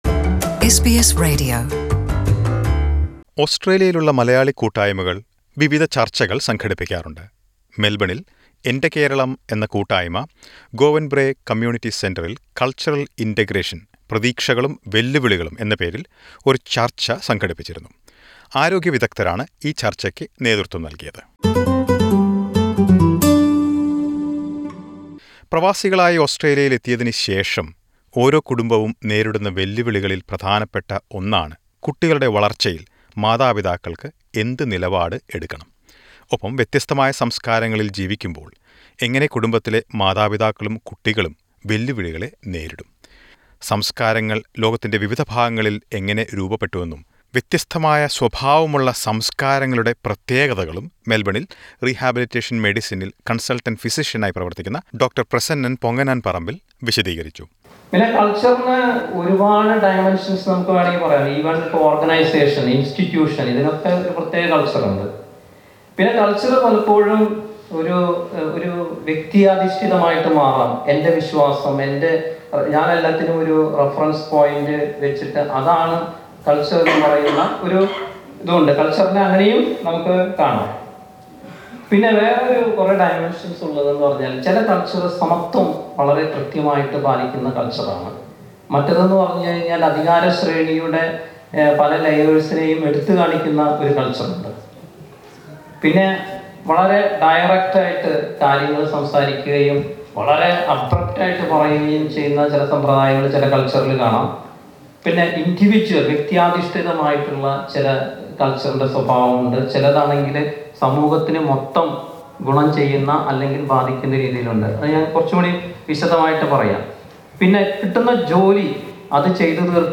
From cultural integration to upbringing of children in a new land pose unexpected challenges to a migrant. Listen to a report on a discussion on this topic organized by community group Ente Keralam in Melbourne and led by health experts.